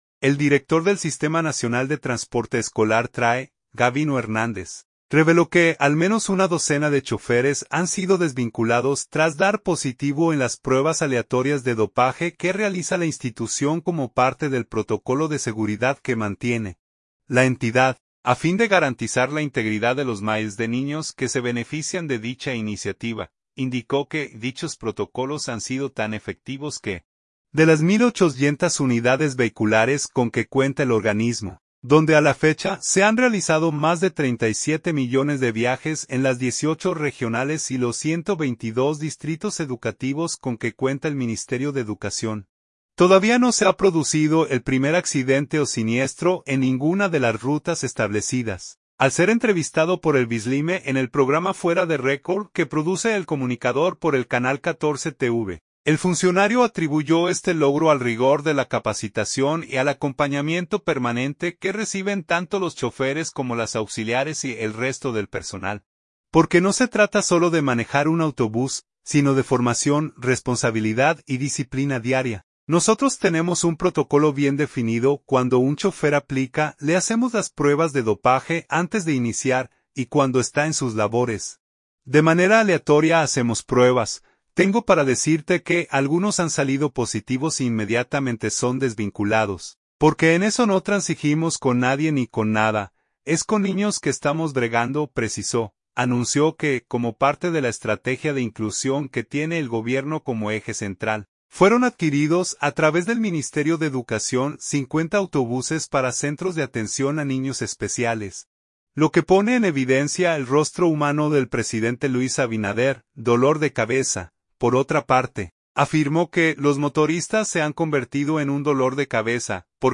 Al ser entrevistado